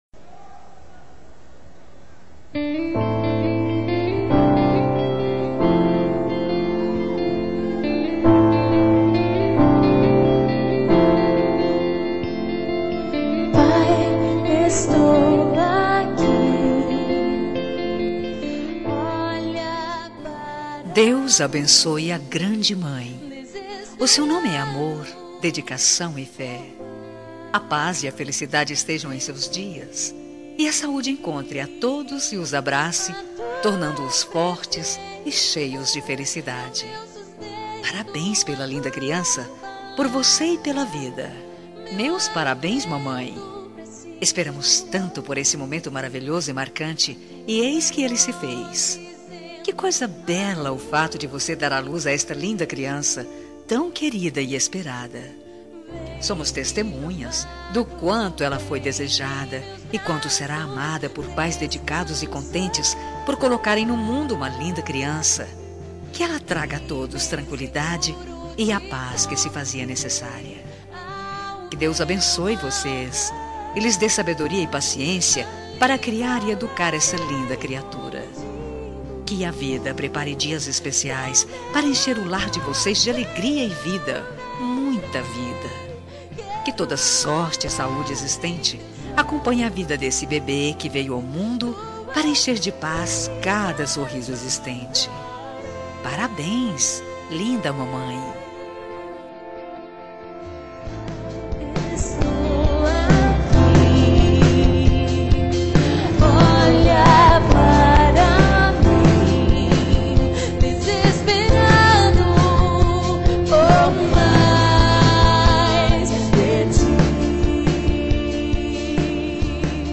TELEMENSAGEM EVANGÉLICA MATERNIDADE
Voz Feminina